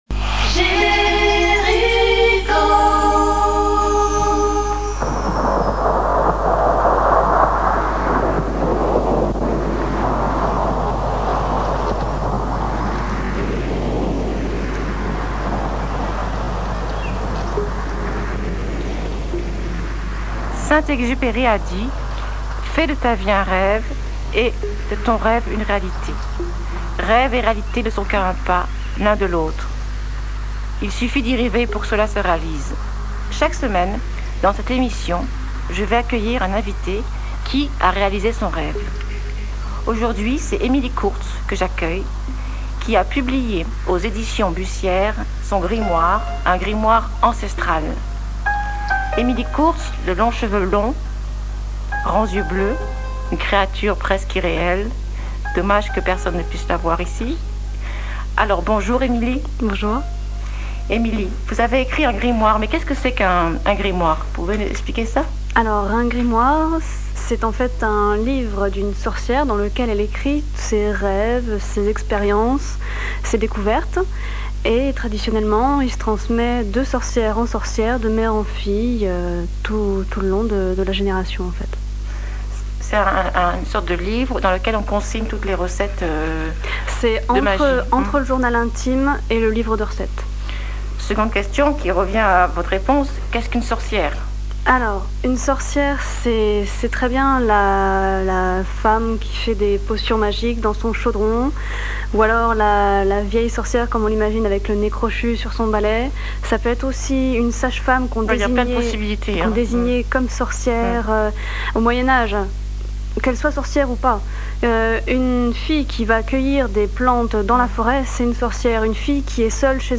Reportage sur Radio Jérico à Metz.